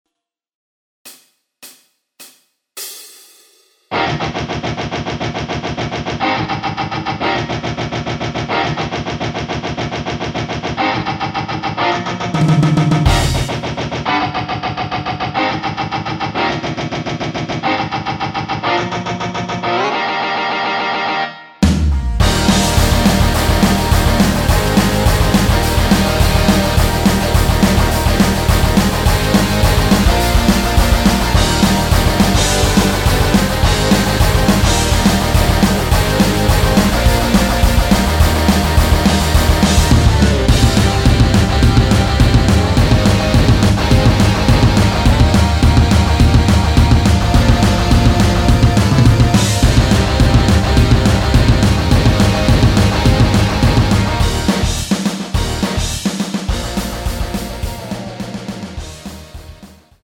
원키에서(-1)내린 MR입니다.
Eb
앞부분30초, 뒷부분30초씩 편집해서 올려 드리고 있습니다.
중간에 음이 끈어지고 다시 나오는 이유는